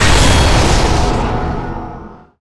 Magic_SpellImpact18.wav